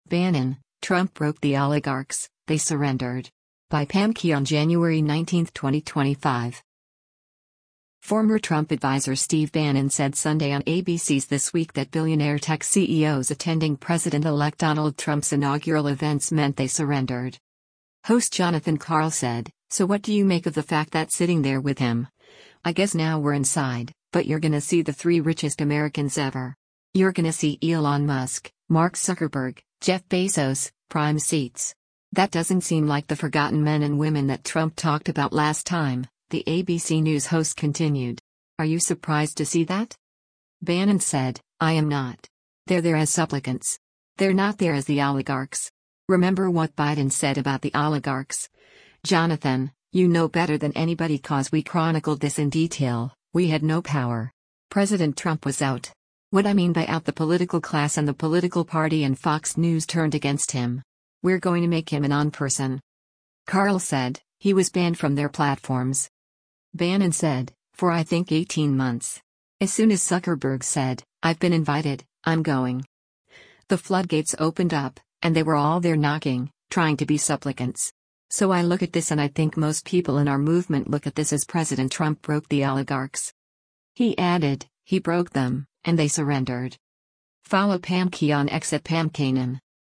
Former Trump adviser Steve Bannon said Sunday on ABC’s “This Week” that billionaire tech CEOs attending President-elect Donald Trump’s inaugural events meant they surrendered.